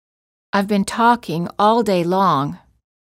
• ストレスをかける単語の主要な母音を長く発音する
※当メディアは、別途記載のない限りアメリカ英語の発音を基本としています